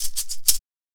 Shaker OS.wav